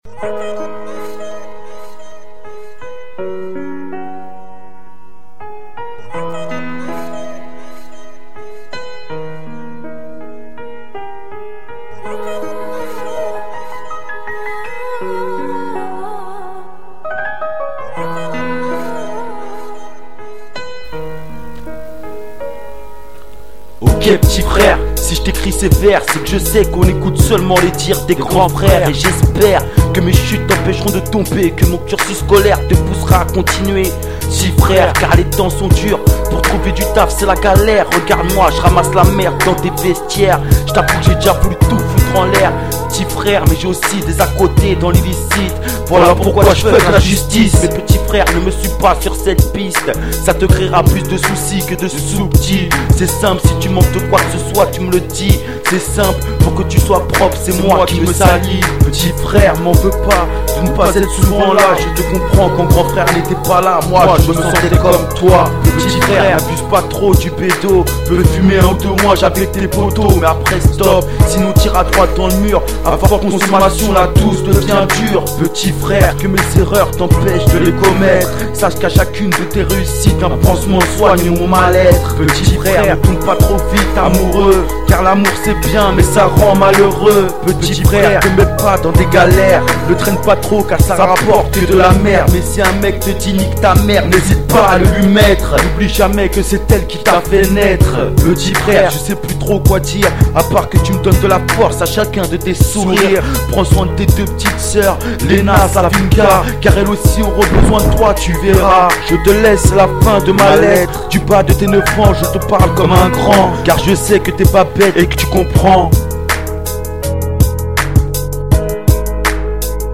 rapeur du 78